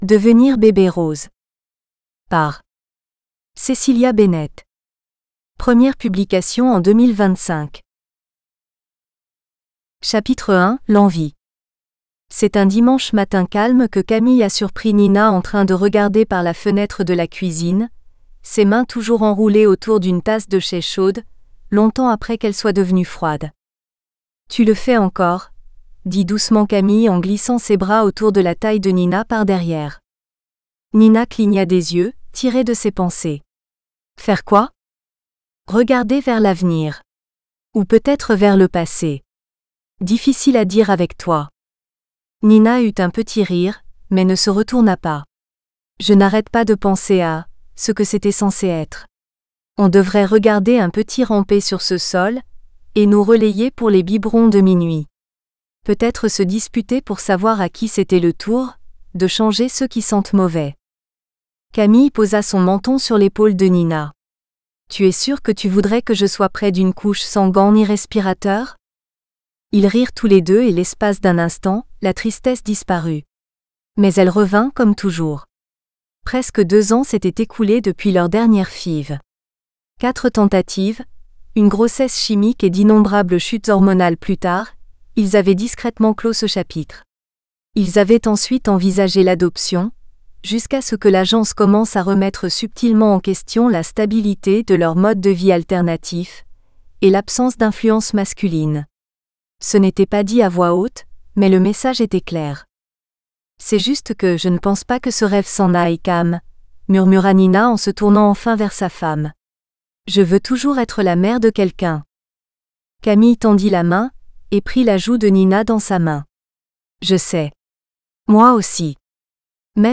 Becoming Baby Rose FRENCH (AUDIOBOOK – female): $US2.99